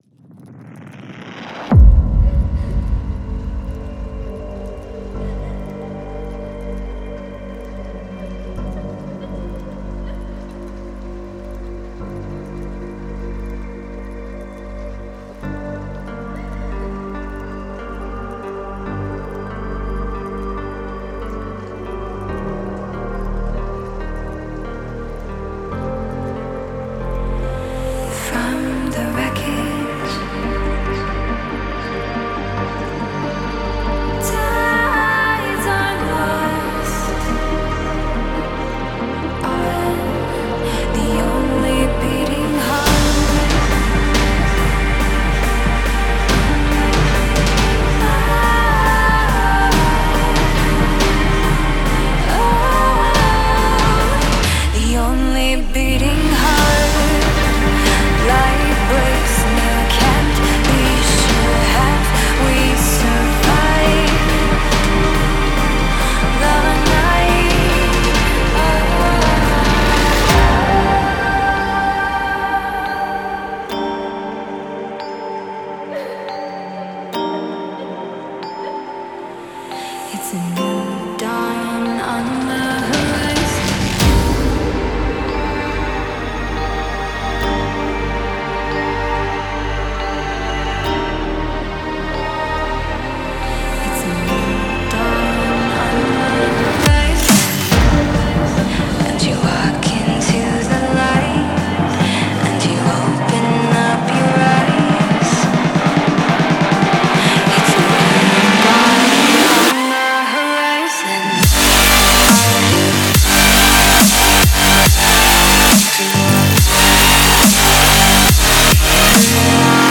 Dramatic, Epic, Triumphant, Uplifting
Vocals